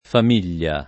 famiglia [ fam & l’l’a ]